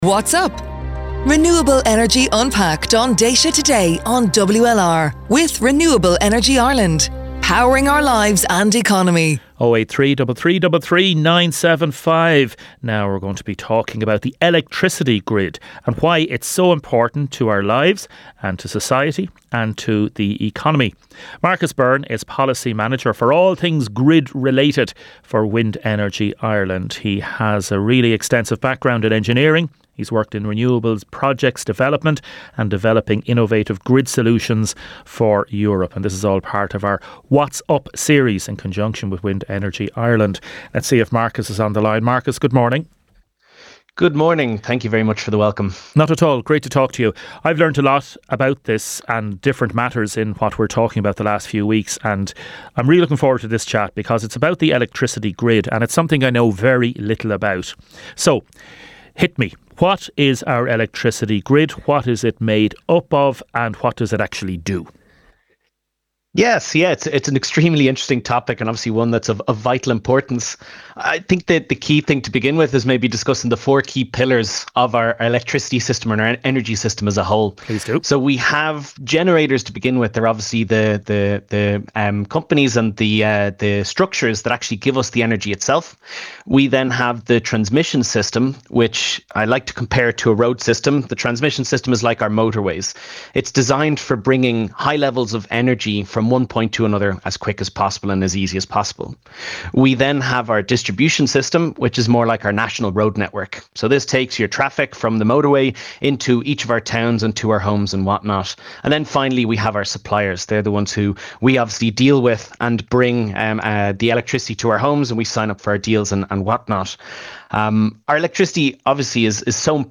Renewable Energy Ireland (REI) partnered with WLR FM in Waterford to host a 12-week series exploring key aspects of renewable energy throughout the autumn and early winter of 2025.